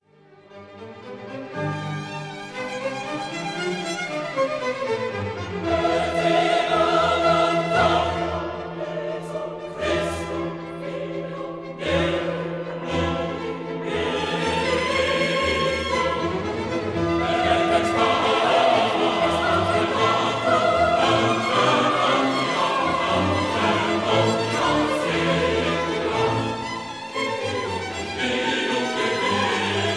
Recorded in the Musikverein, Vienna in October 1956 (Stereo)